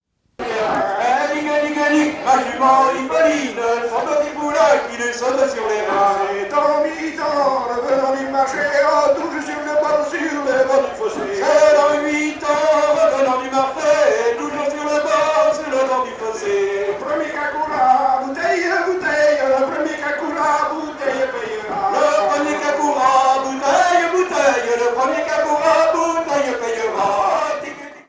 fest-noz de Monterfil en juin 88